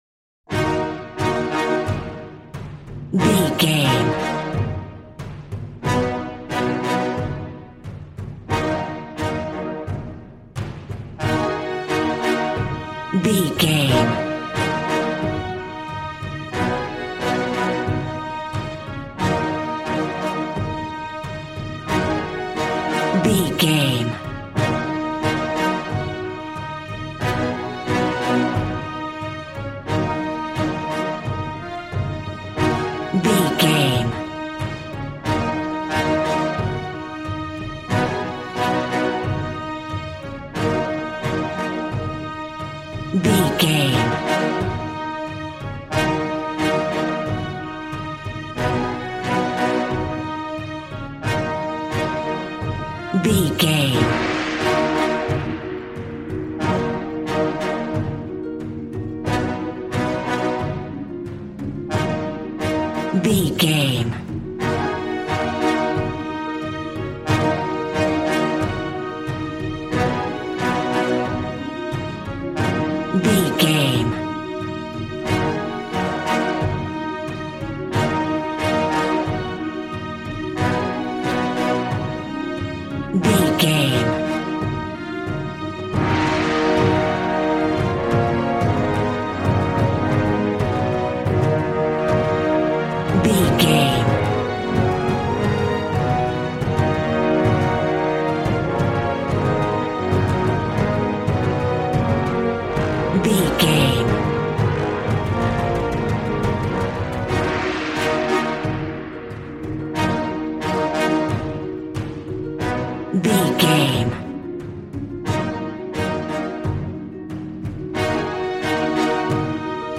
Regal and romantic, a classy piece of classical music.
Aeolian/Minor
E♭
regal
cello
double bass